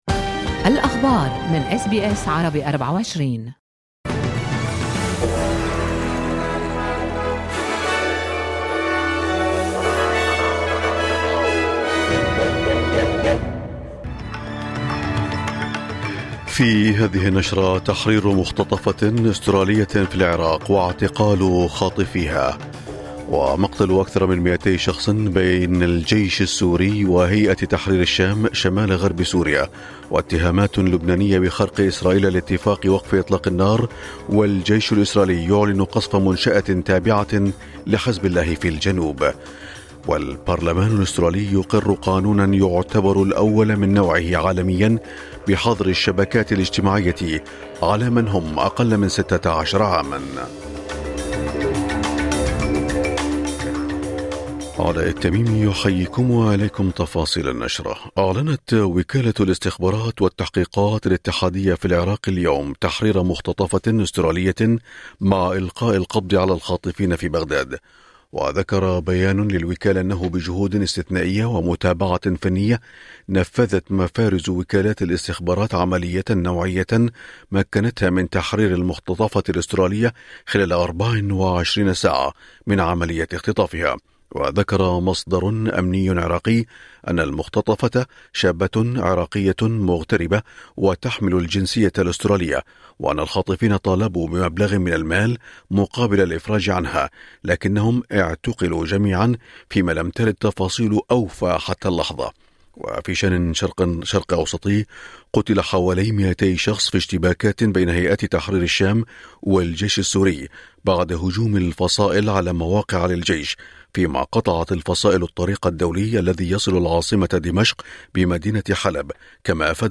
نشرة أخبار الصباح 29/11/2024